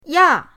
ya4.mp3